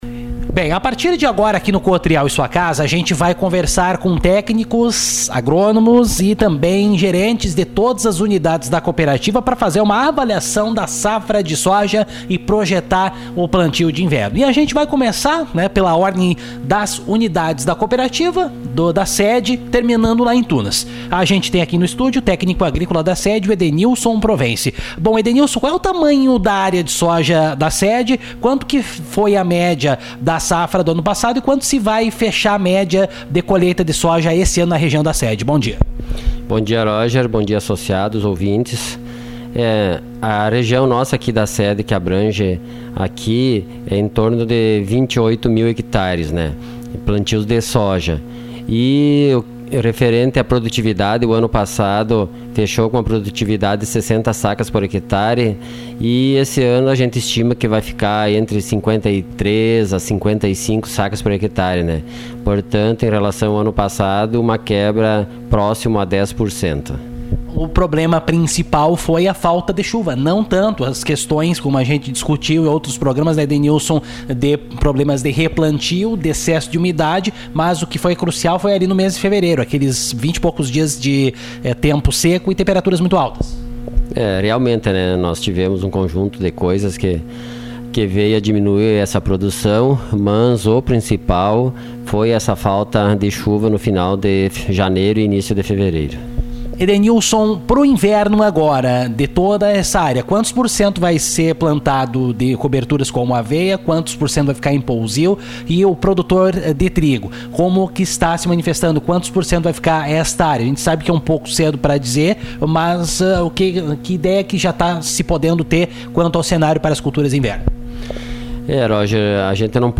aqui a reportagem.